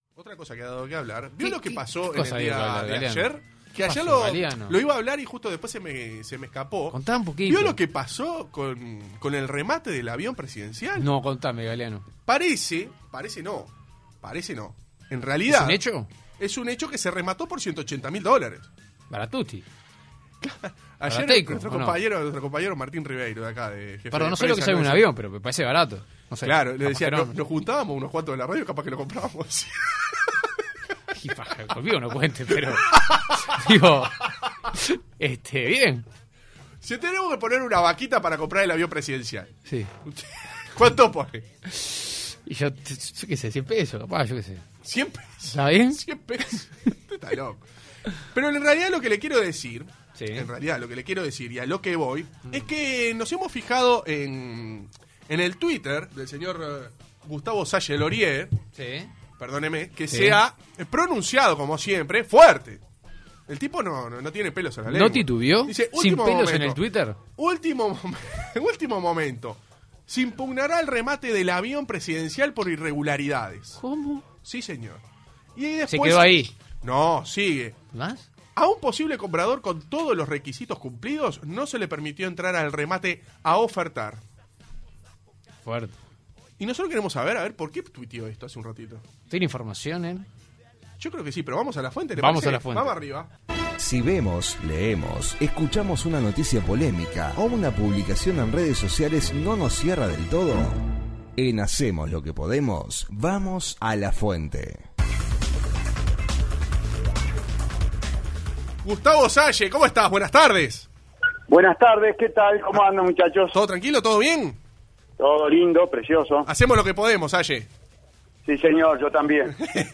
Gustavo Salle fue el protagonista de la sección Vamos a la fuente del programa Hacemos lo que podemos.